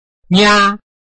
拼音查詢：【饒平腔】ngia ~請點選不同聲調拼音聽聽看!(例字漢字部分屬參考性質)